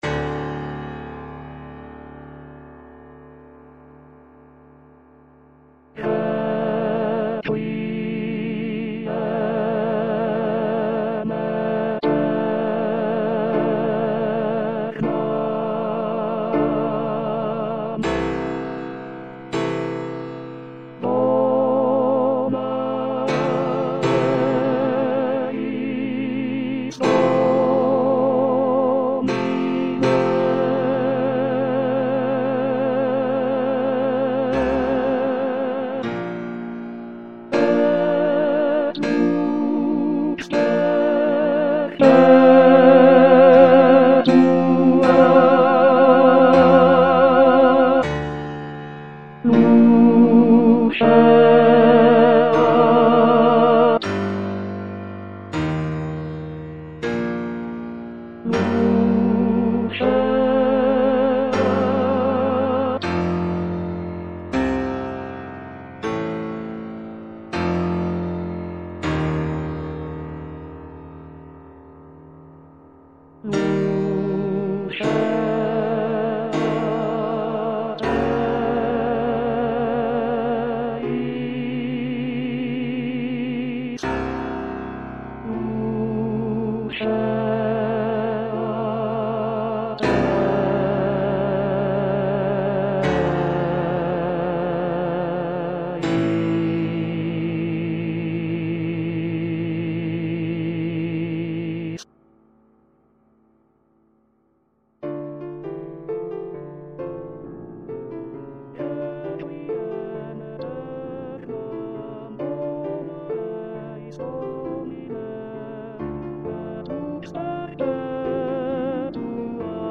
ATTENTION : ces fichiers audio comportent peu ou pas de nuances, il ne s'agit (normalement!) que des bonnes notes à la bonne place
avec la bonne durée chantées par des voix synthétiques plus ou moins agréables .